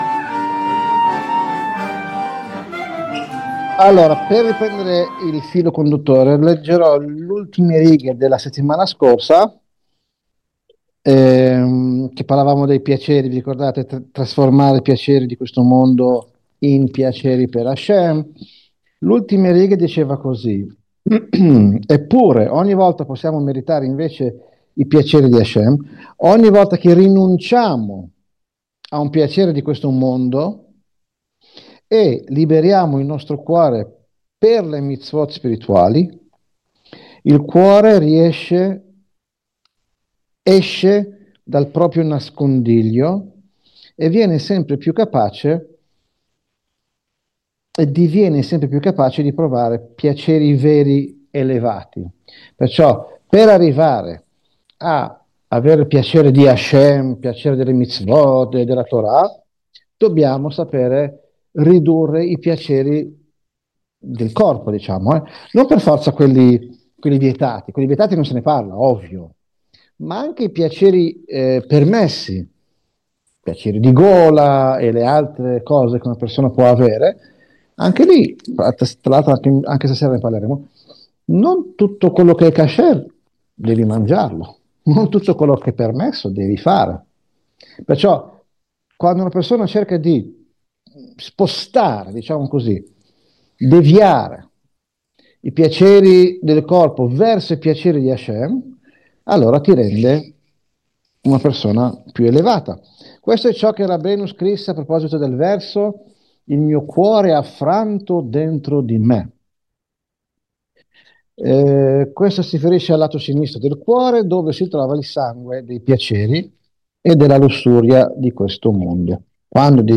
Lezione del 29 novembre 2025